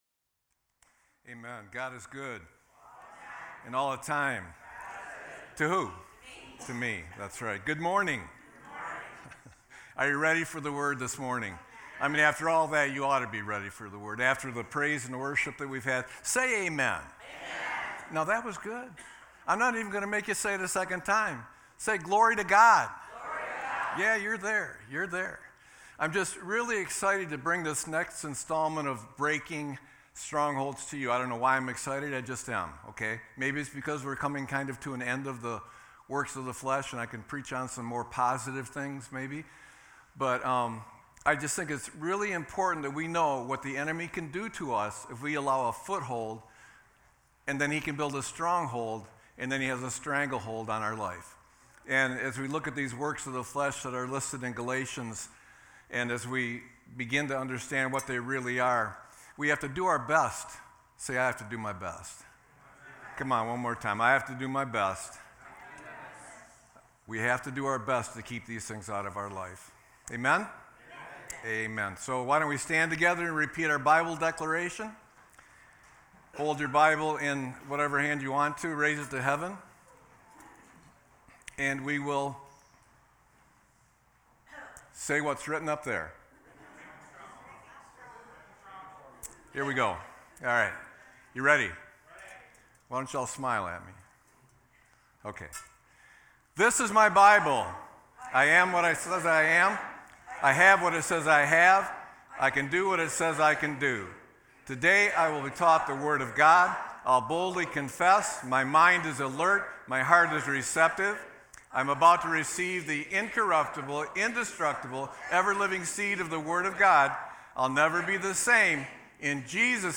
Sermon-5-19-24.mp3